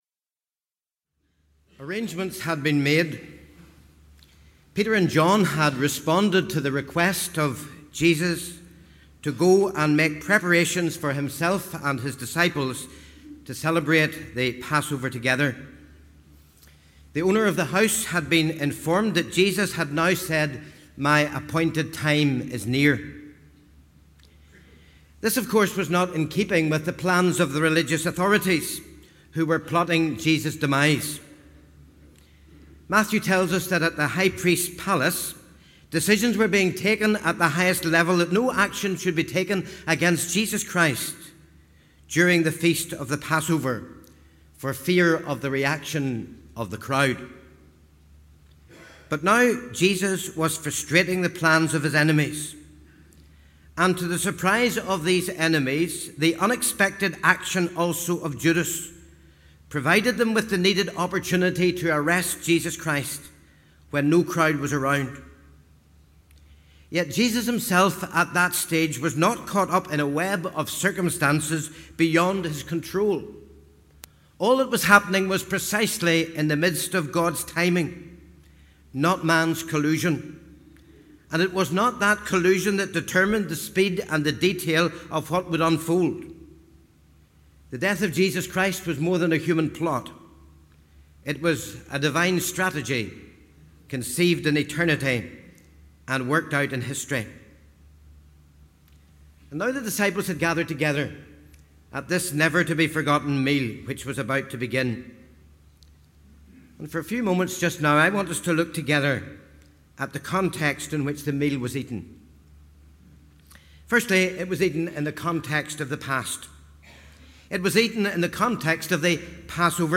Address of the Moderator, Dr Ian McNie, at the Communion Service of the 2015 General Assembly.
The Assembly met in Assembly Buildings, Belfast from Monday, 1st June until Thursday, 4th June, 2015.